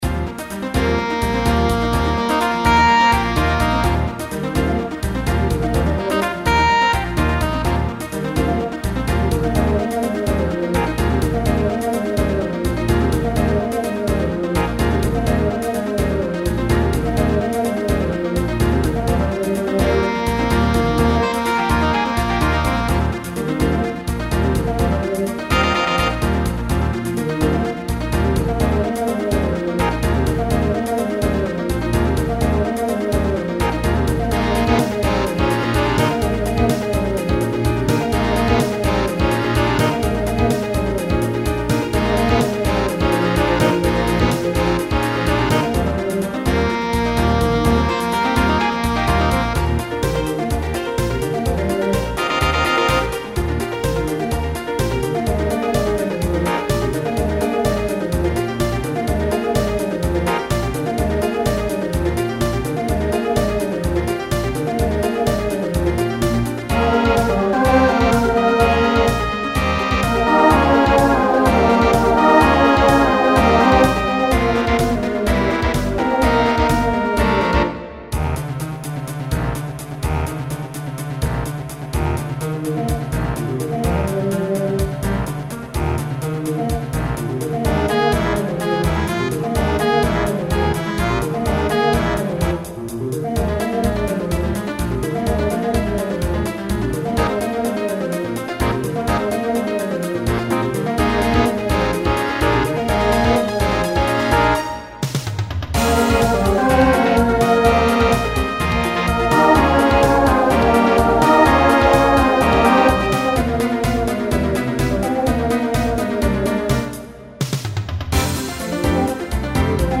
Genre Broadway/Film , Rock Instrumental combo
Voicing SATB